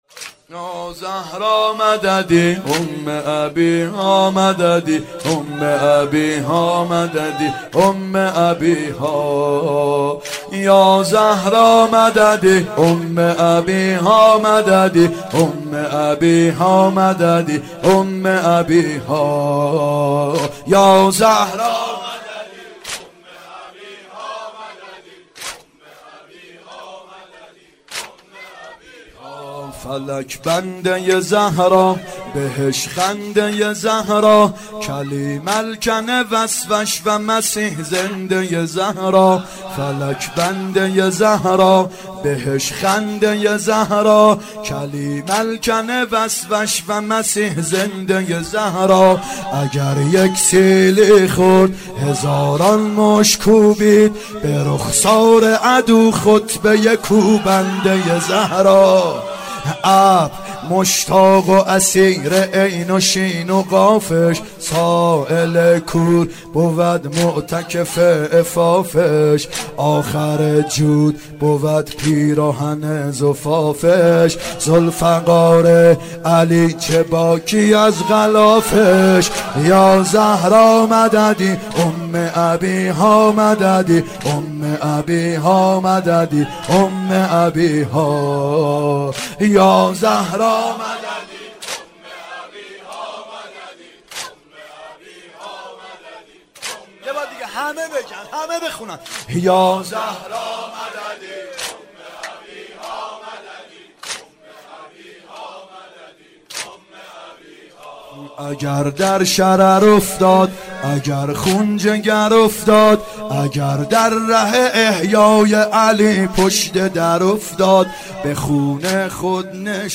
فاطمیه اول هیئت یامهدی (عج)